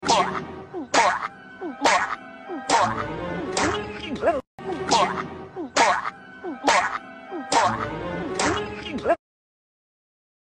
Follow for more deep fried FreakBob sound effects free download